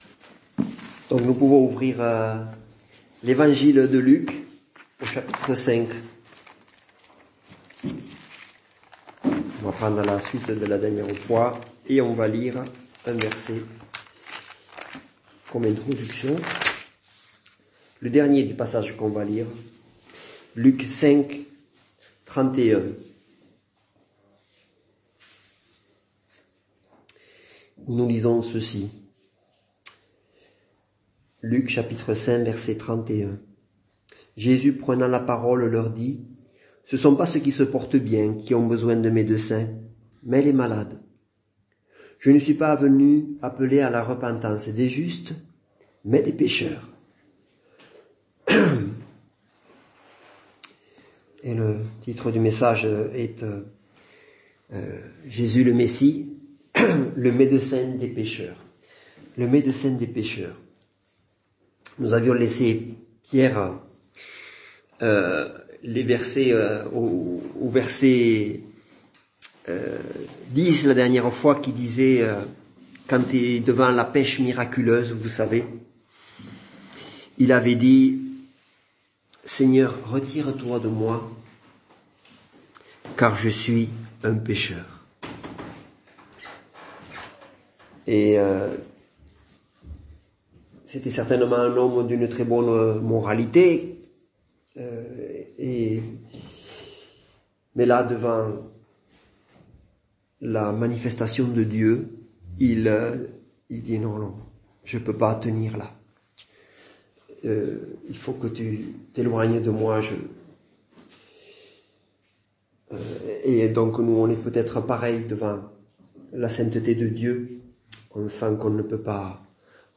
Culte du dimanche 21 novemre 2022 - EPEF